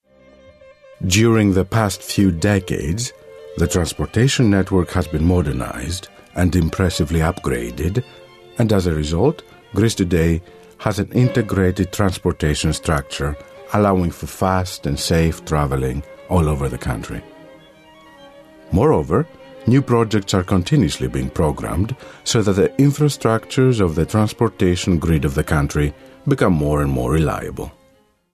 Greek, Male, 30s-40s